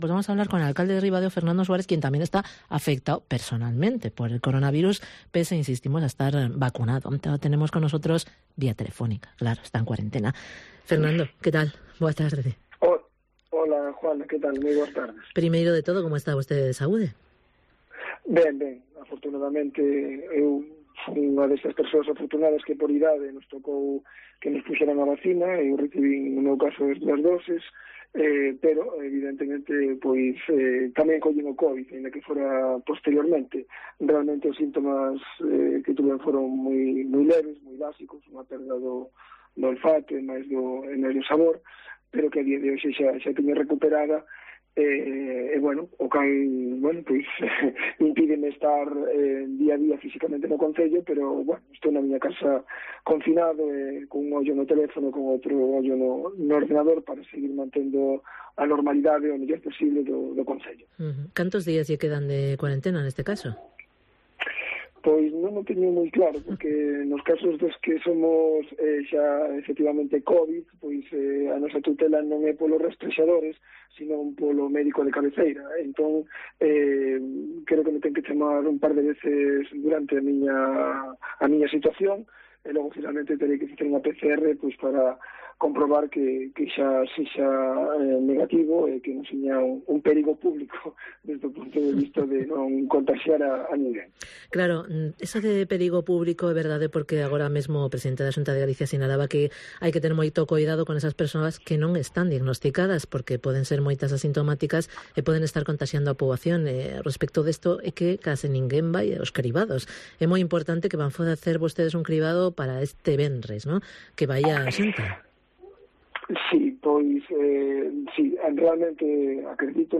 Entrevista al alcalde de Ribadeo, Fernando Suárez (foto de archivo)